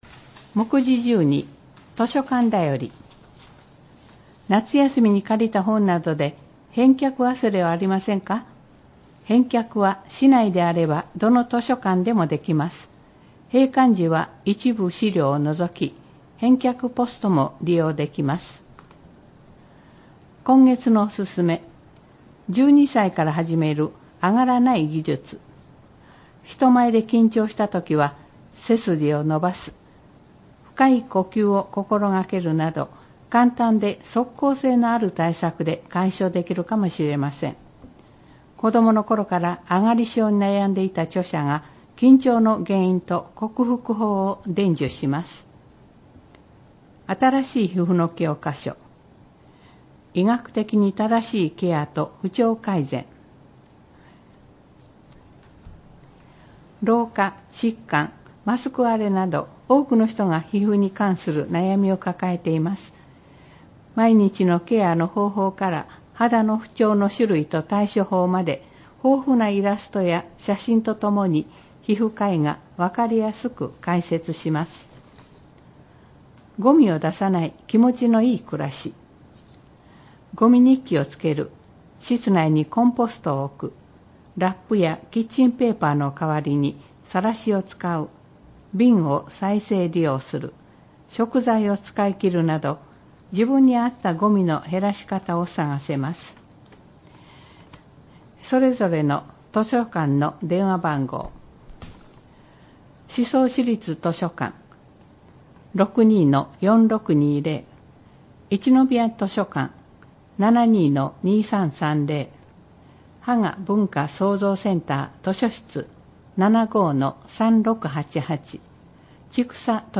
読み上げ録音：朗読ボランティアふきのとう データの提供：デイジー宍粟